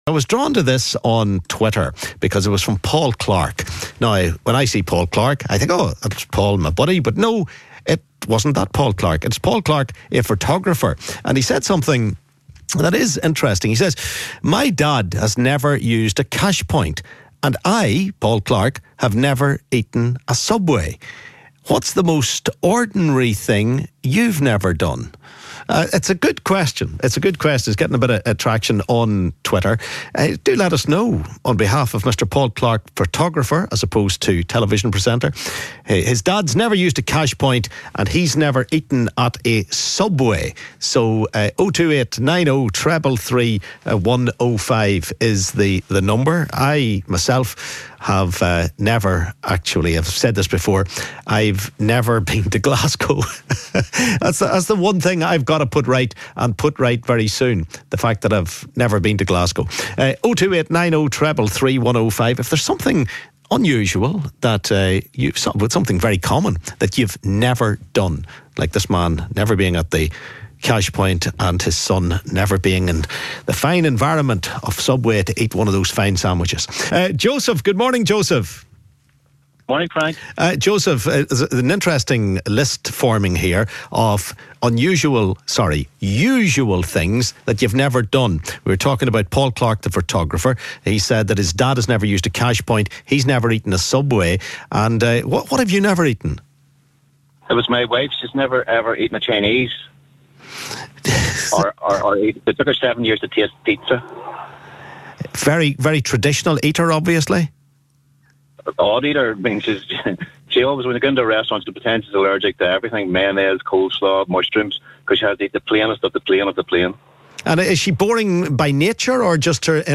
chats to some of the listeners...